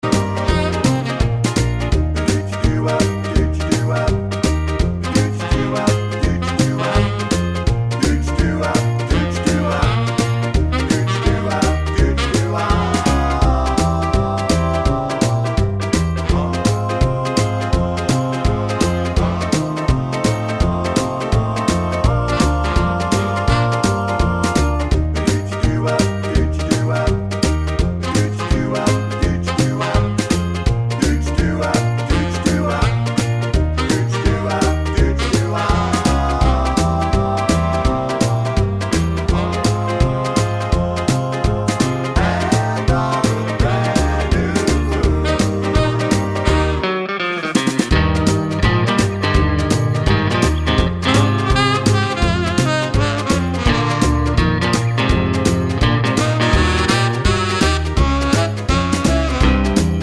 no Backing Vocals Soul / Motown 2:31 Buy £1.50